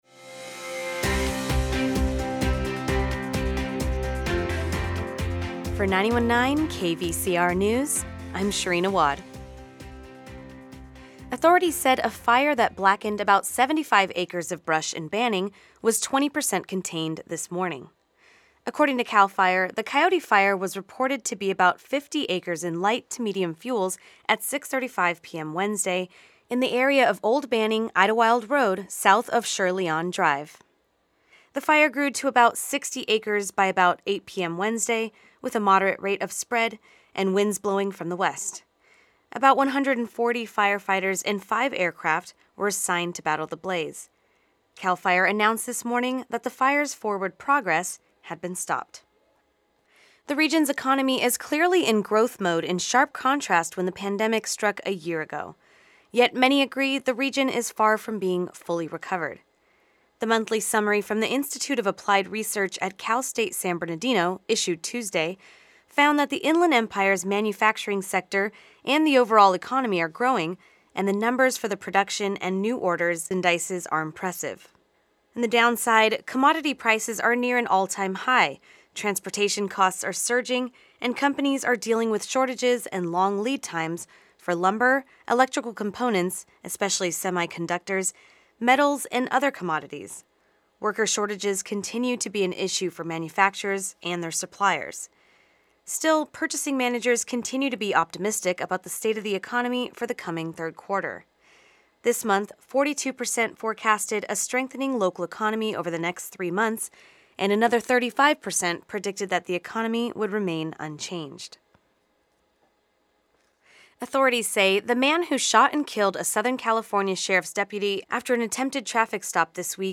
KVCR News has your daily news rundown at lunchtime.